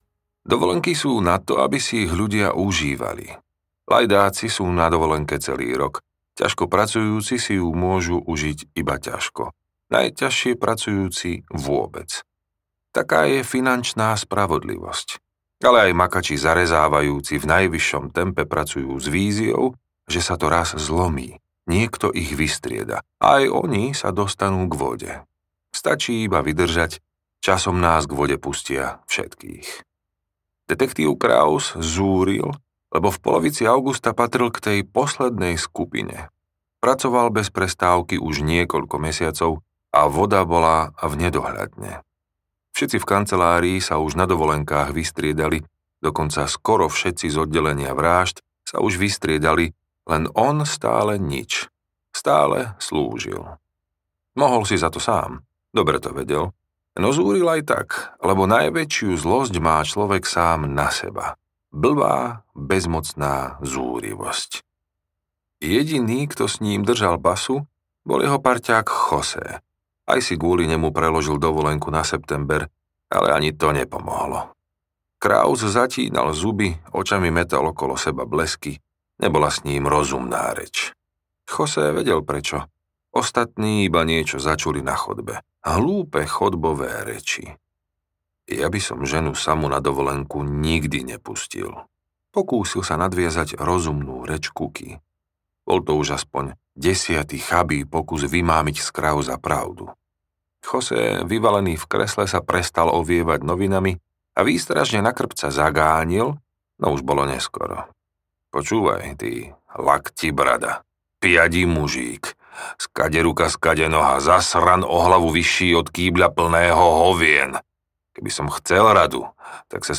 Nevinným sa neodpúšťa audiokniha
Ukázka z knihy